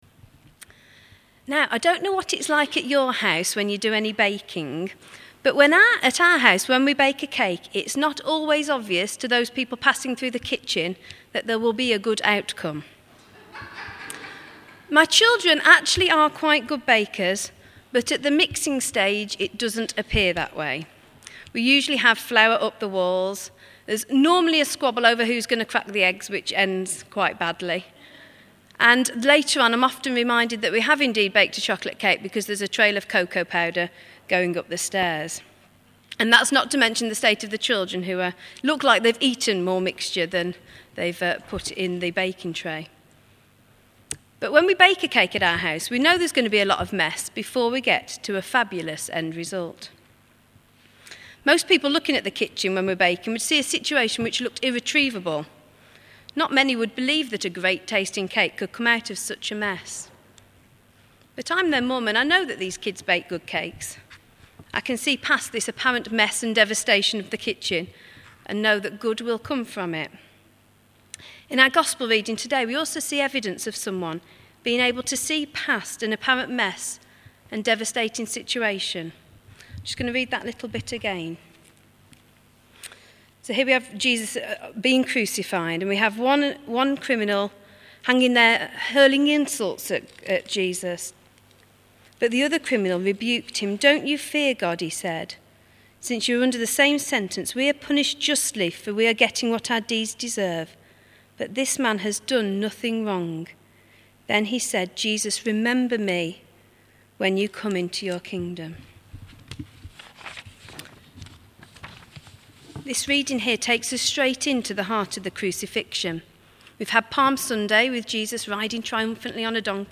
Talk
in 10:30 Morning Worship, St John's service